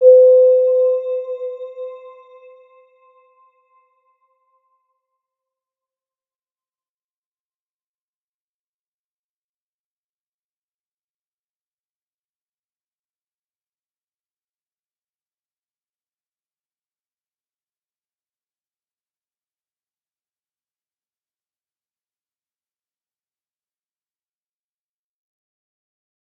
Round-Bell-C5-mf.wav